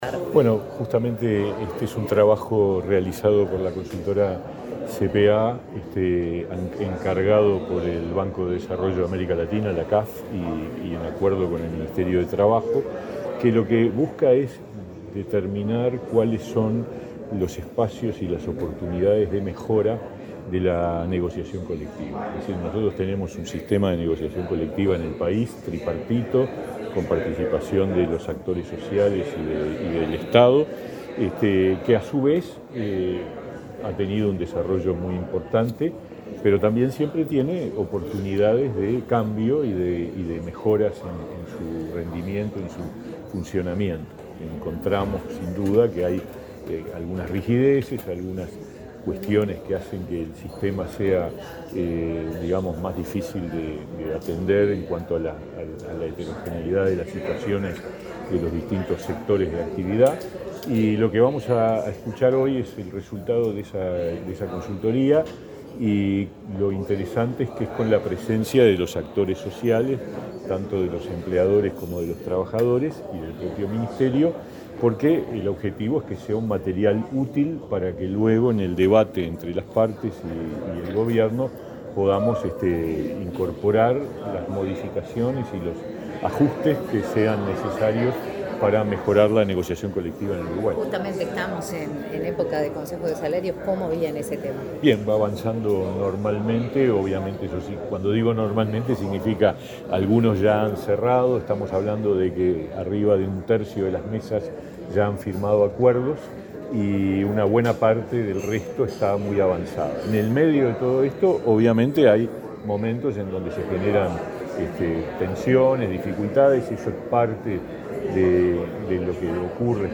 Declaraciones del ministro de Trabajo, Pablo Mieres
El ministro de Trabajo, Pablo Mieres, dialogó con la prensa luego de participar, este martes 10 en Montevideo, de la presentación del informe